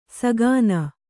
♪ sagāna